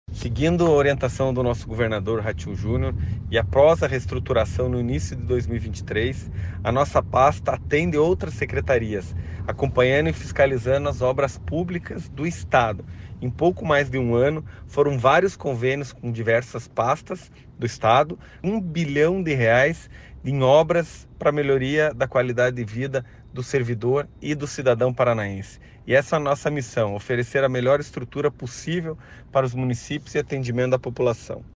Sonora do secretário Estadual das Cidades, Eduardo Pimentel, sobre os convênios da pasta de R$ 1 bilhão para construção de equipamentos públicos